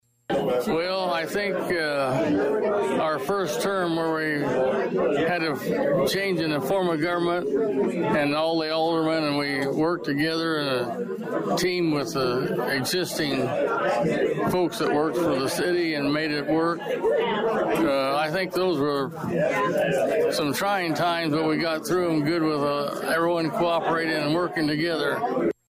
The occasion was an early birthday reception for Jones, who was Danville’s mayor from 1987 until 2003.  He was asked what made him most proud during those years….
The reception honoring Jones took place prior to the regular city council meeting.